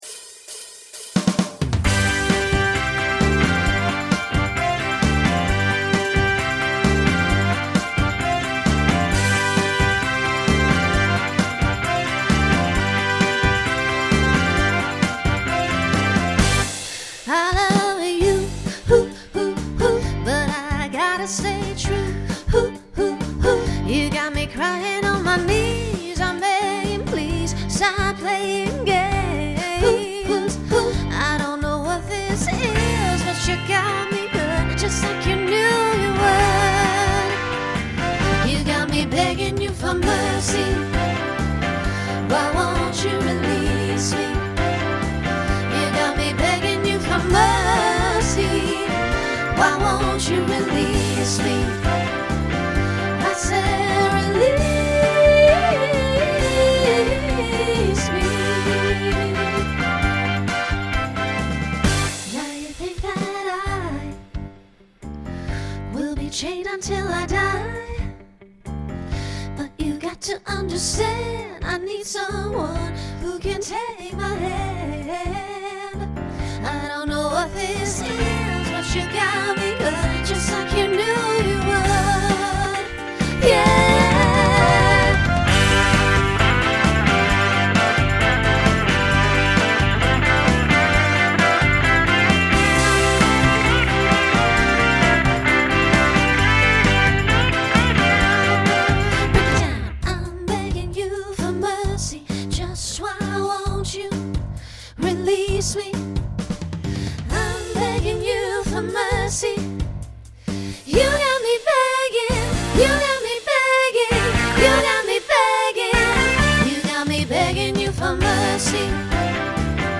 Pop/Dance
Transition Voicing SSA